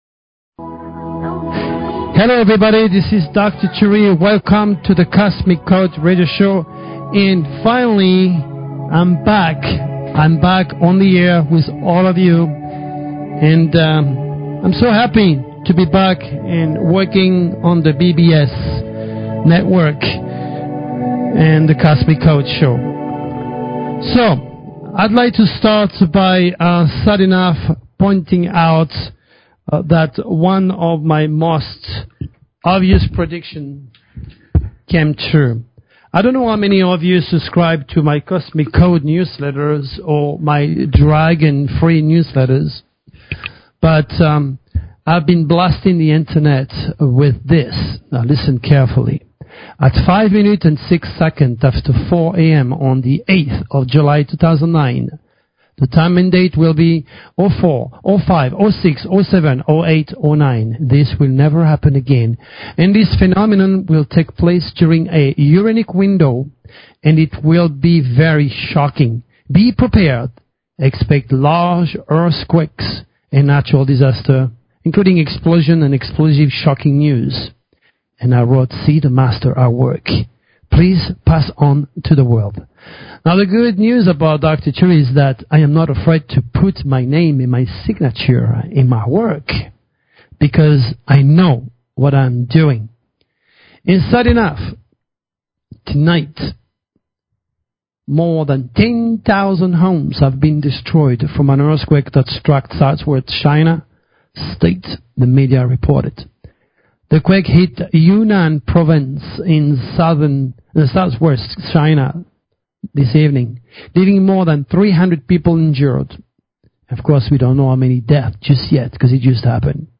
Talk Show Episode, Audio Podcast, The_Cosmic_Code and Courtesy of BBS Radio on , show guests , about , categorized as